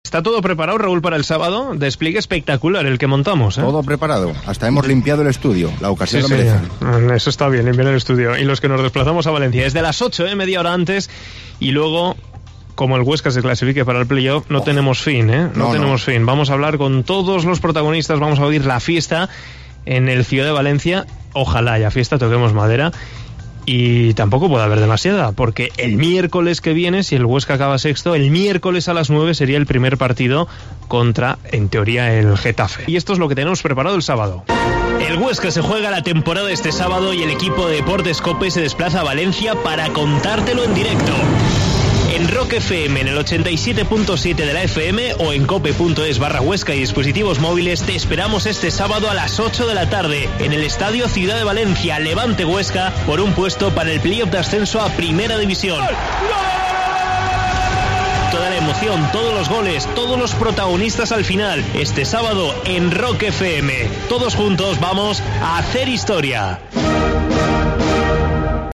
Promo especial para el Levante - Huesca